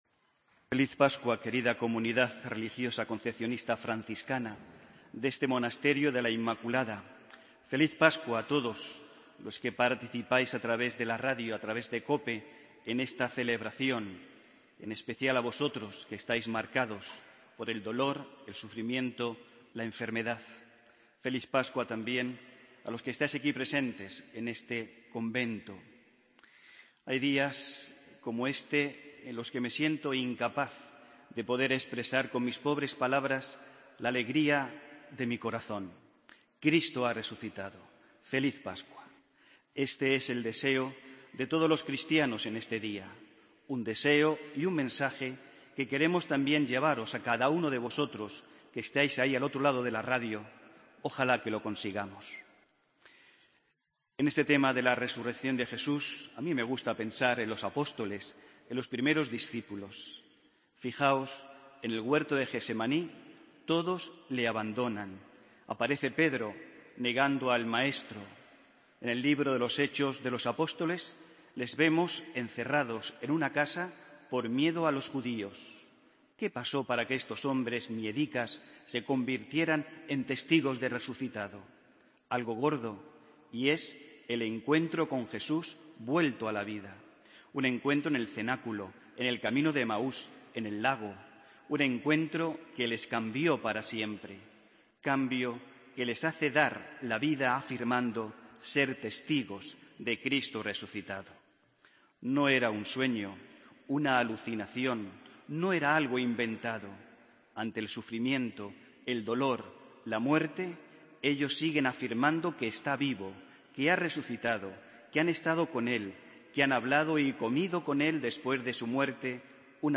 Homilia del domingo 5 de abril de 2015